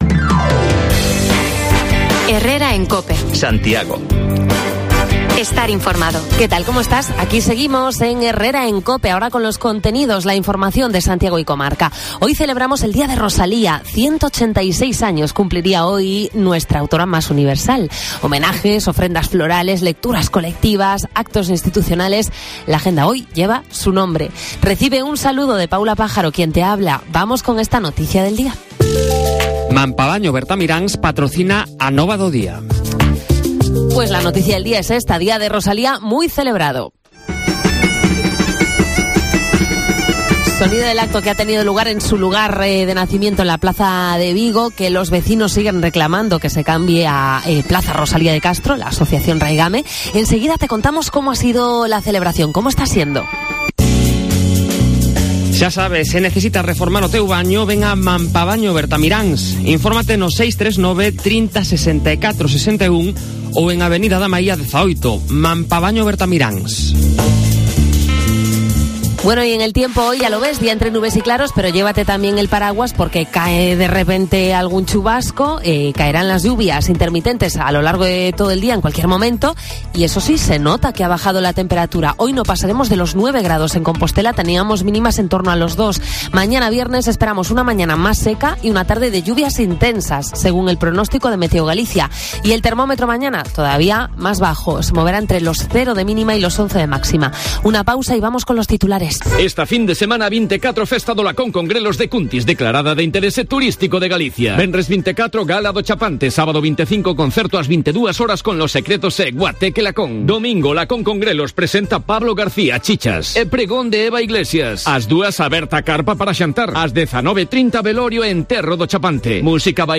Te contamos la celebración del Día de Rosalía en Compostela con una conexión en directo con el Colegio Pío XII, donde se ha programado una lectura colectiva